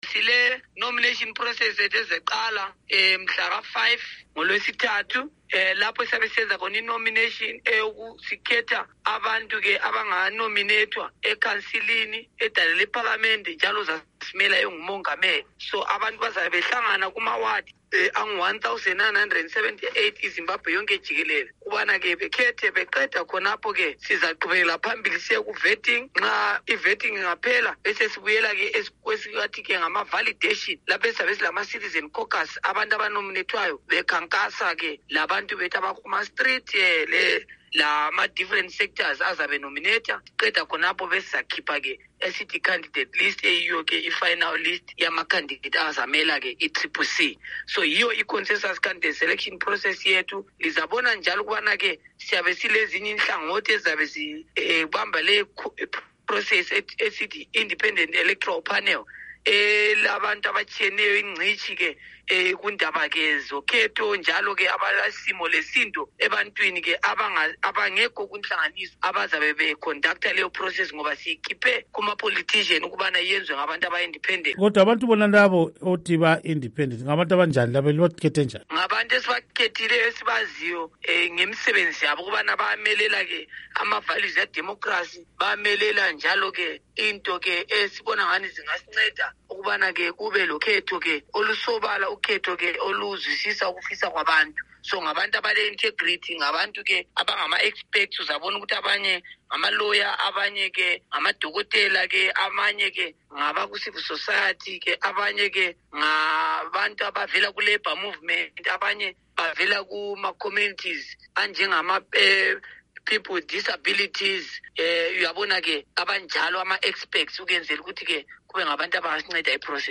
Ingxoxo Esiyenze LoMnu. Gift Ostallos Siziba